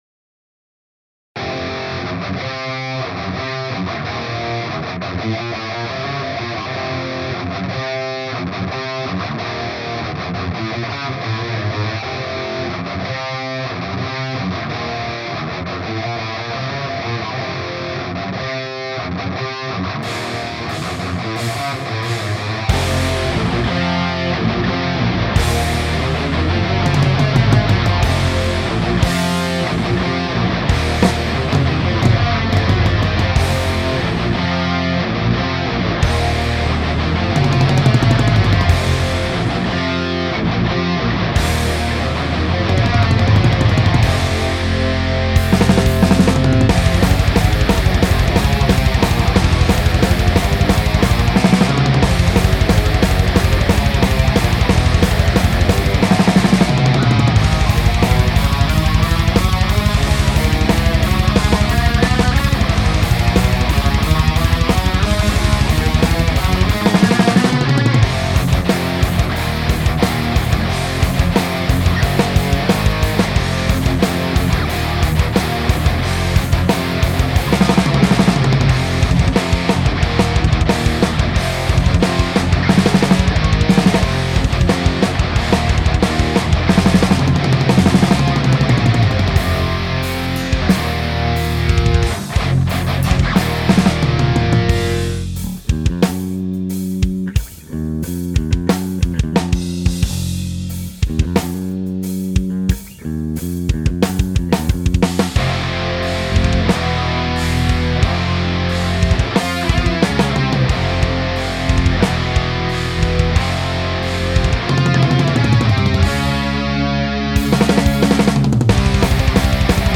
I play for a black/death metal band and am currently recording material for the full length album. I finished tracking guitars and bass for the first song and am using superior drummer to program the drums.
Namely - The drums sound very plastic(save for the kick). No power in the snare and toms, seems like sticks are hitting on paper. Overall level is very low and I have no headroom left after applying my master plug ins.
The guitar tones are out of a Line6 POD HD500X. I used a Dark Glass B7K for the bass.